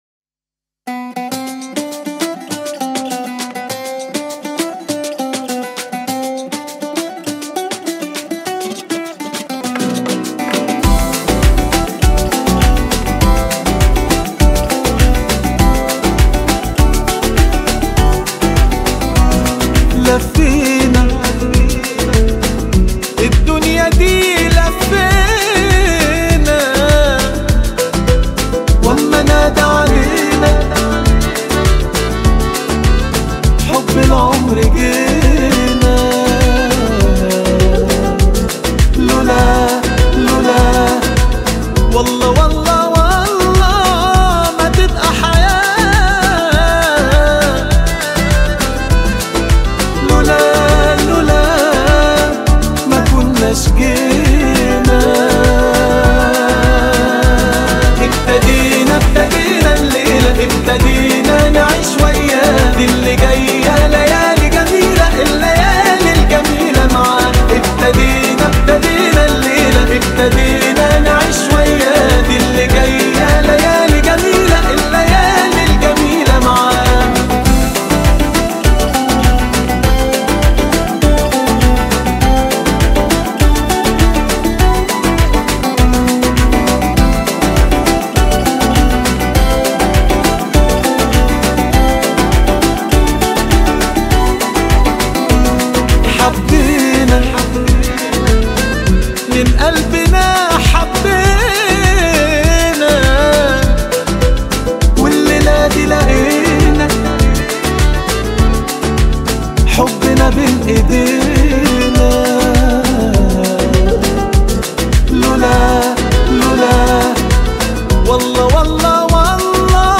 • النوع الموسيقي: بوب عربي – رومانسي